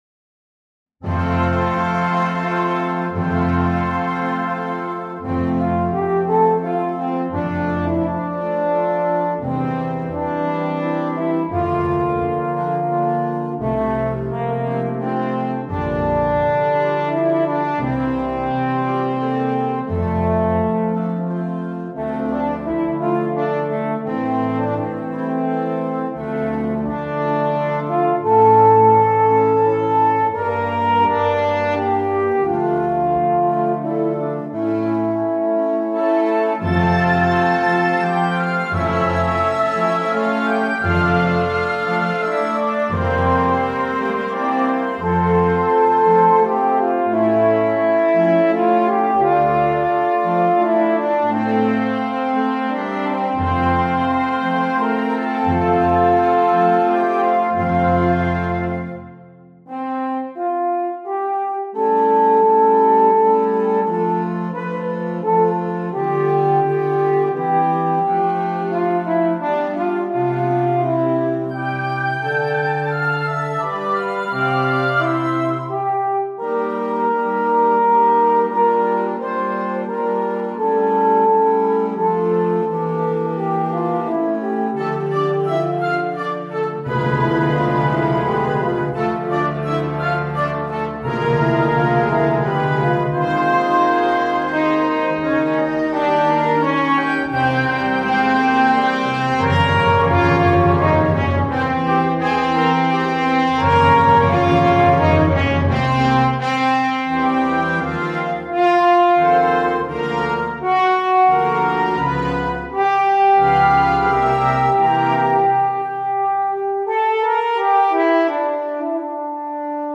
with solo instrument
Eb Tenor Horn or French Horn (Solo)
Classical
Music Sample